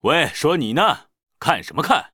文件 文件历史 文件用途 全域文件用途 Kagon_amb_02.ogg （Ogg Vorbis声音文件，长度2.1秒，116 kbps，文件大小：30 KB） 源地址:地下城与勇士游戏语音 文件历史 点击某个日期/时间查看对应时刻的文件。 日期/时间 缩略图 大小 用户 备注 当前 2018年5月13日 (日) 02:14 2.1秒 （30 KB） 地下城与勇士  （ 留言 | 贡献 ） 分类:卡坤 分类:地下城与勇士 源地址:地下城与勇士游戏语音 您不可以覆盖此文件。